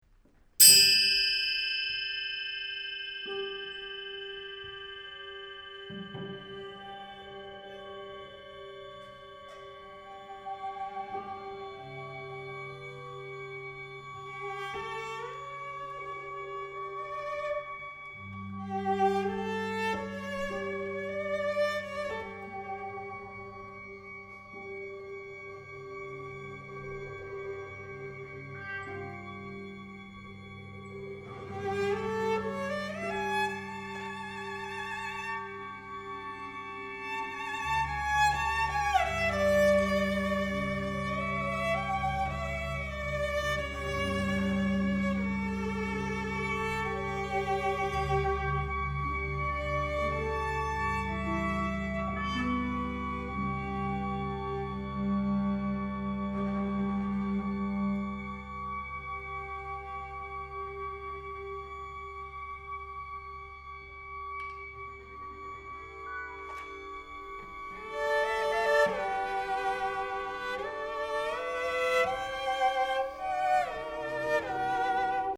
violoncelle solo